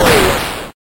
描述：复古视频游戏8位爆炸
Tag: 复古 爆炸 8位 视频游戏